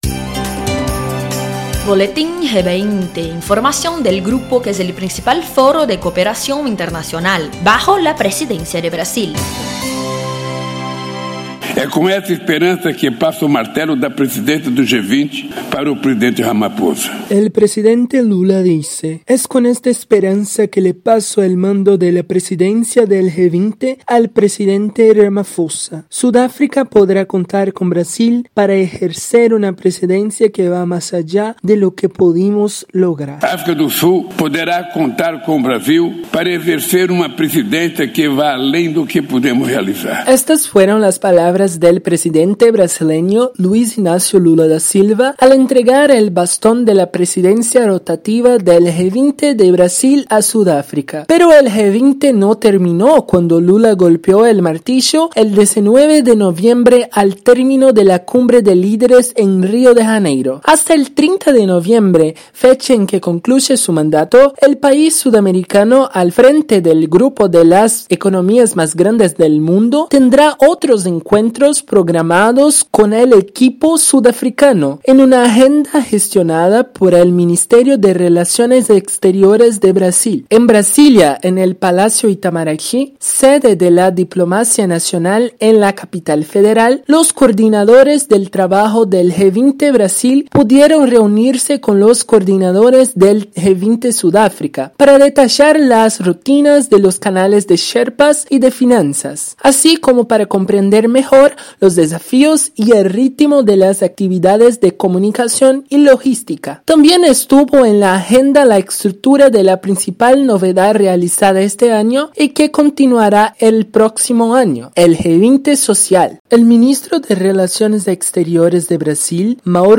El país que una vez fue sede de Eco-92 y la primera edición del Foro Social Mundial vuelve a traducir varios idiomas en un mensaje común: no hay posibilidad de un mundo más justo y un planeta más sostenible sin escuchar a la sociedad civil organizada. Escucha el reportaje e infórmate más.